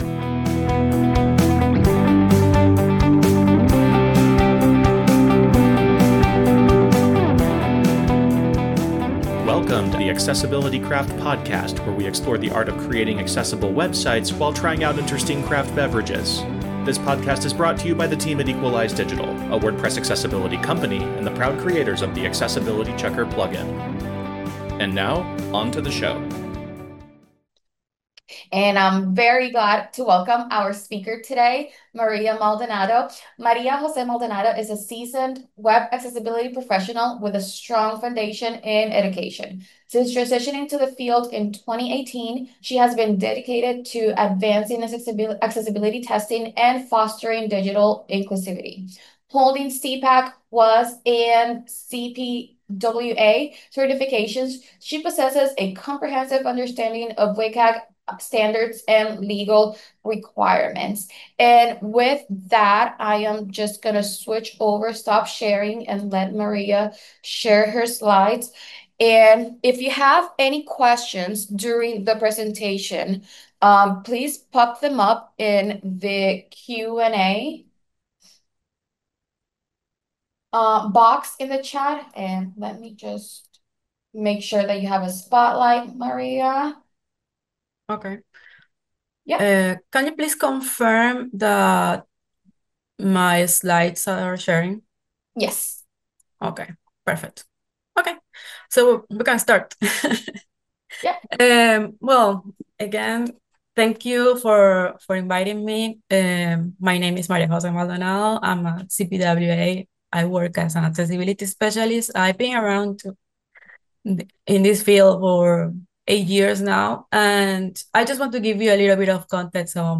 This episode is a recording of a June 2025 WordPress Accessibility Meetup